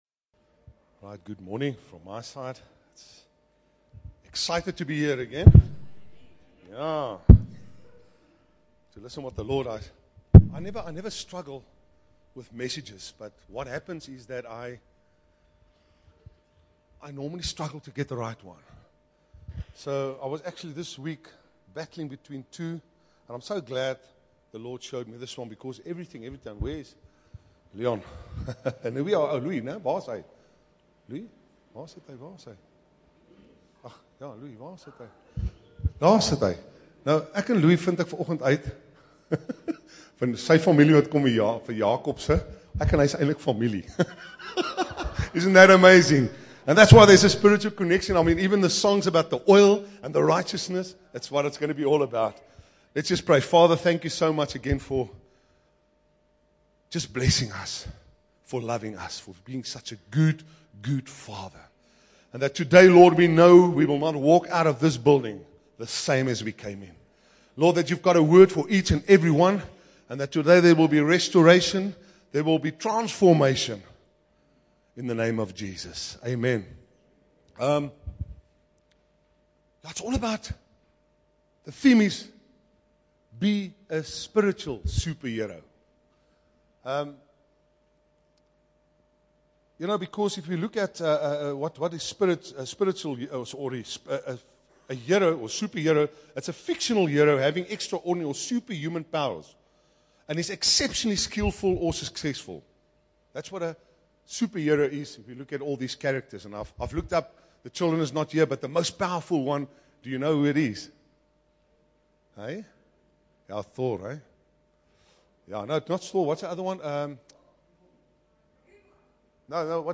Bible Text: Psalm 52: 6 – 8, Psalm 128: 3 | Preacher: